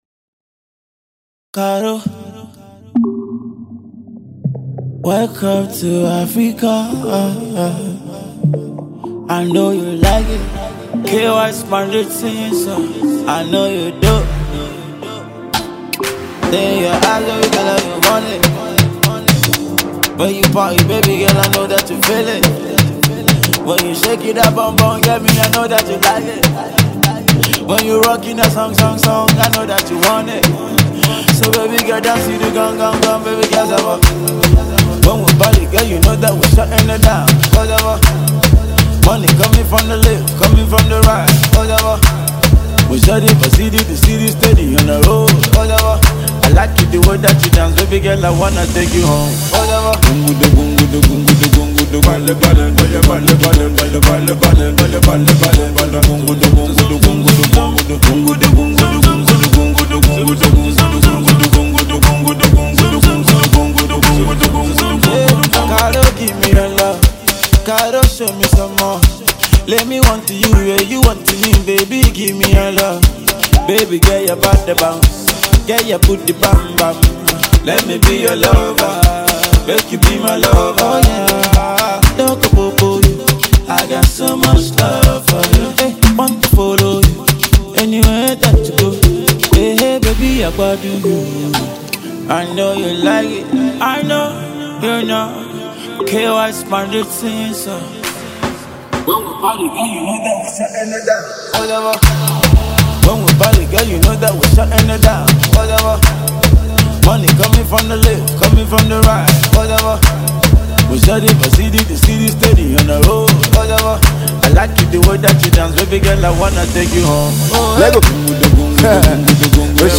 smooth vocals provide the melodic foundation
distinctive rap flair
catchy hooks
witty bars